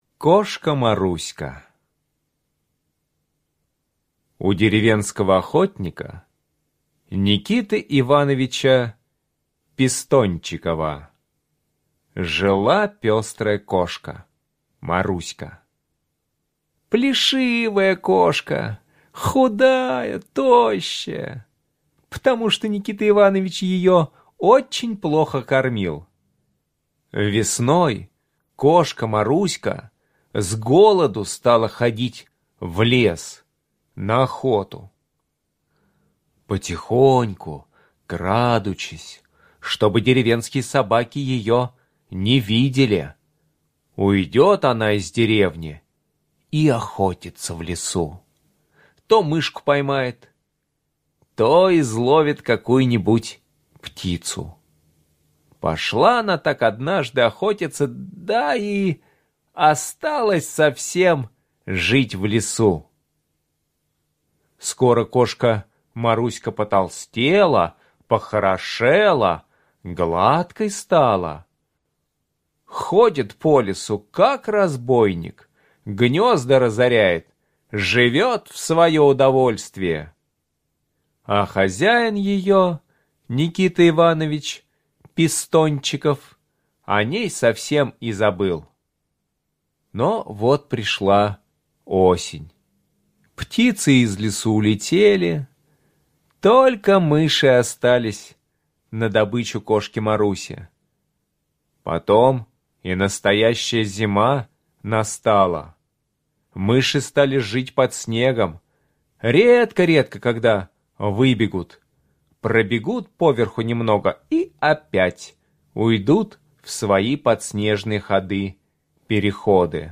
На данной странице вы можете слушать онлайн бесплатно и скачать аудиокнигу "Кошка Маруська" писателя Евгений Чарушин.